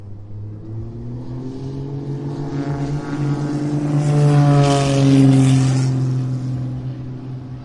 红牛航空赛飞机起飞 " takeoff2
描述：在红牛航空竞赛波尔图2017年的螺旋桨驱动的飞机起飞